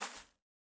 Bare Step Grass Medium E.wav